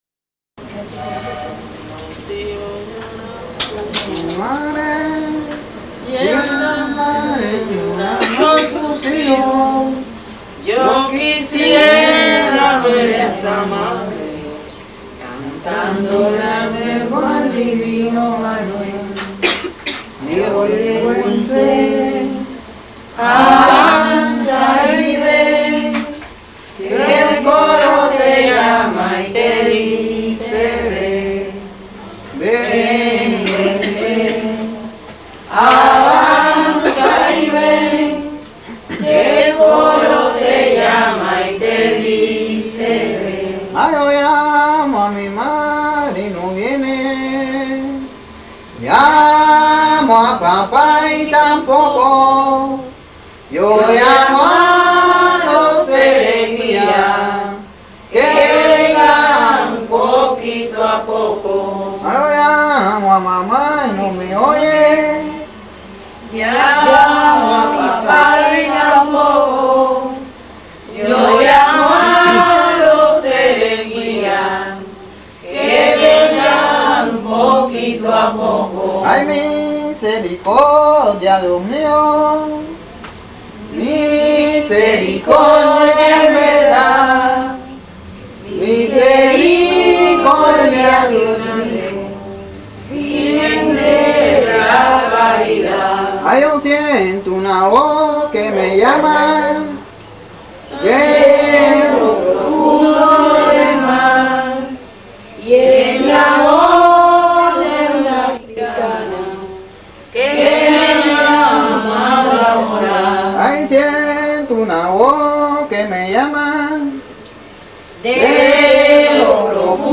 obtenue d'enregistrements faits à Cuba